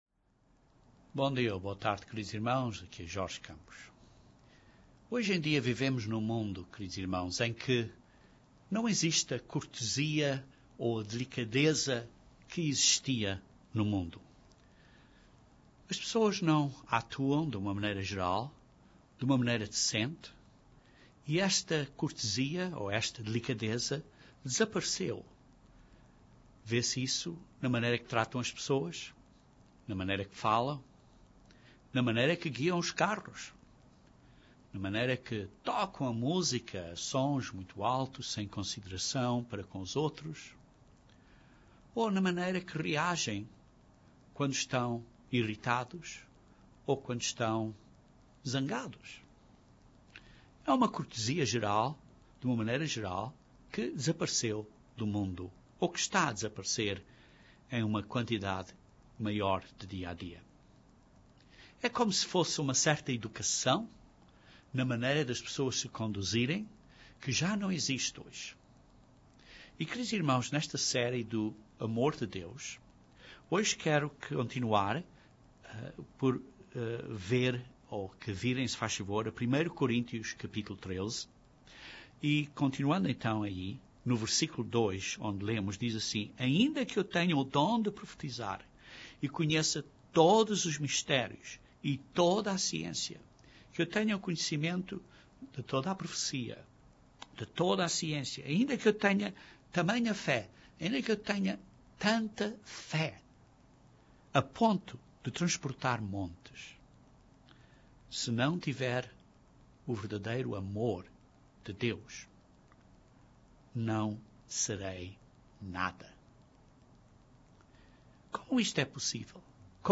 Este sermão nesta série de sermões do amor de Deus cobre uma outra característica do amor de Deus. O amor não se conduz inconvenientemente.